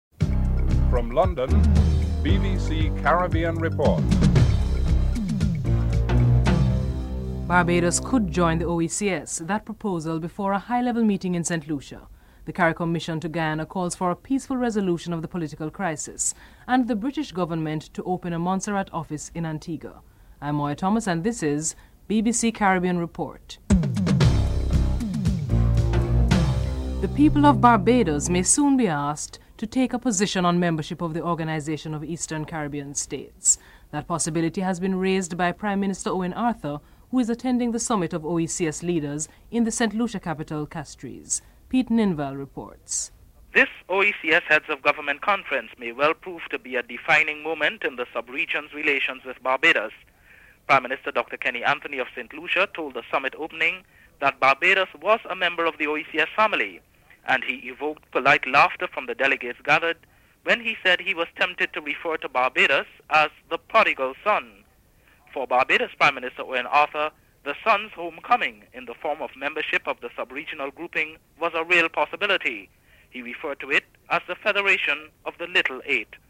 OECS Ambassador to Brussels, Edwin Laurent comments on how these proposals compared to the region's expectations.